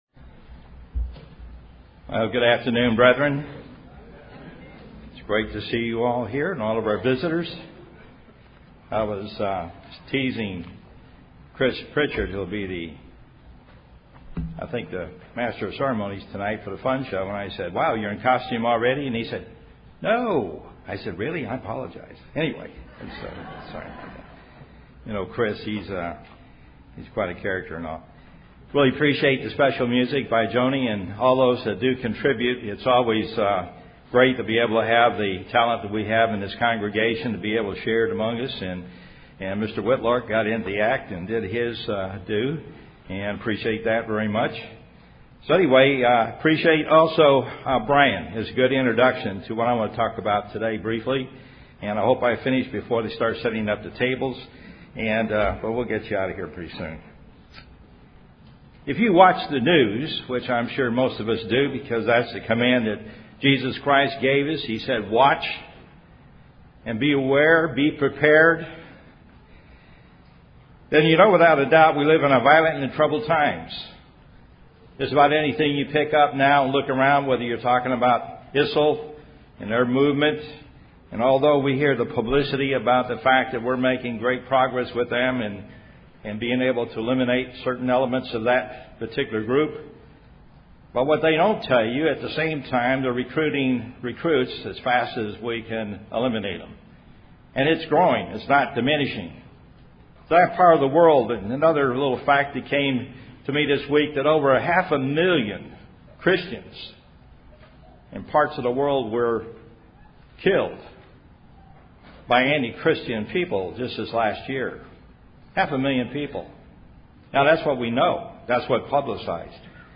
Given in Dallas, TX
UCG Sermon Studying the bible?